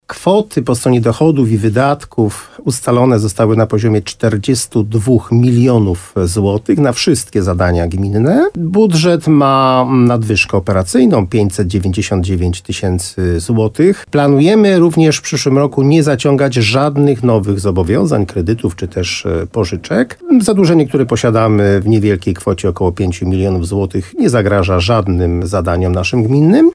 Jak ocenił w programie Słowo za Słowo na antenie RDN Nowy Sącz wójt gminy Moszczenica Jerzy Wałęga, budżet jest stabilny i bardzo rozwojowy.